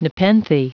Prononciation du mot nepenthe en anglais (fichier audio)
Prononciation du mot : nepenthe